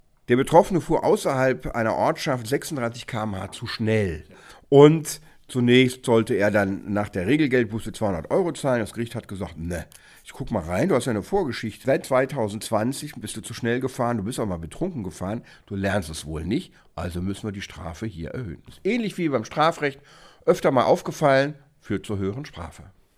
O-Ton + Kollegengespräch: Risiko für Wiederholungstäter steigt: Dürfen weitere Verstöße zu einem höheren Bußgeld führen?